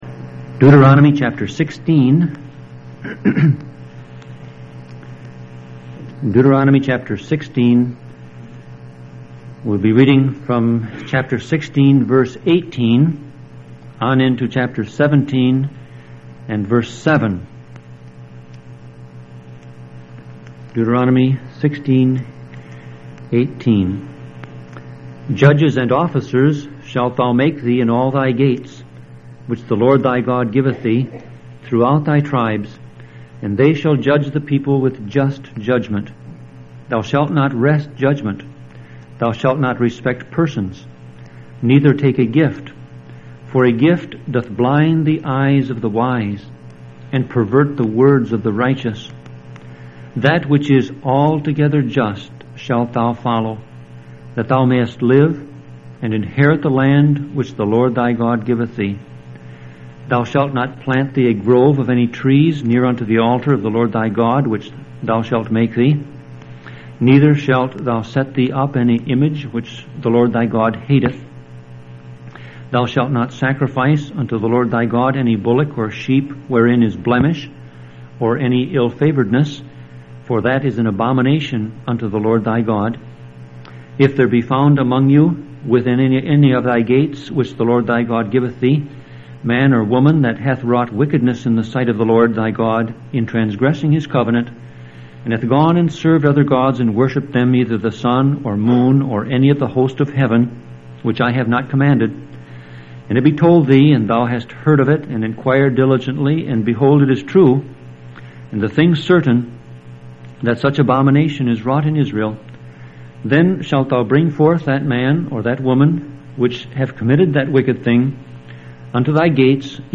Sermon Audio Passage: Deuteronomy 16:18-17:7 Service Type